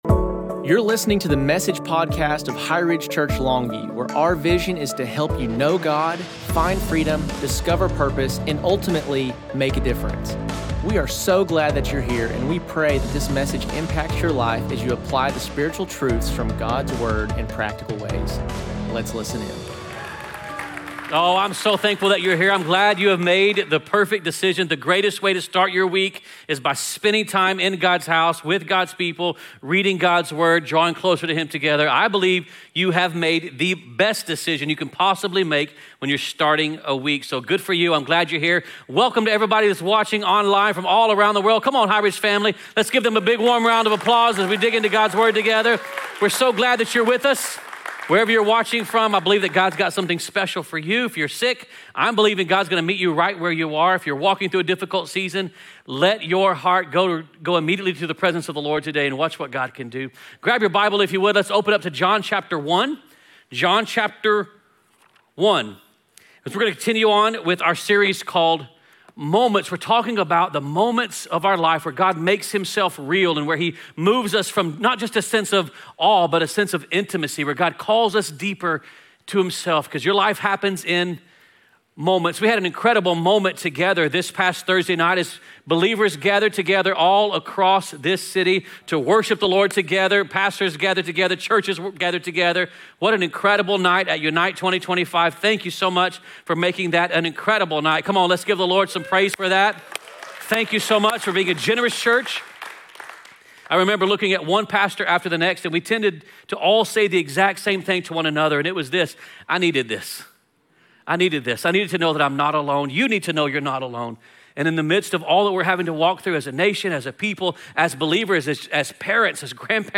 2025 Message